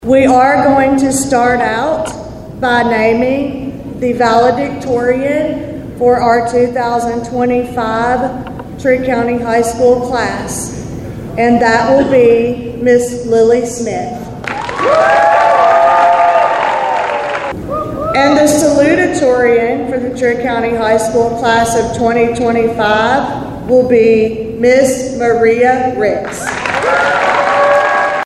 The Trigg County High School class of 2025 was honored and celebrated Tuesday morning during the annual scholarship day at the high school gym.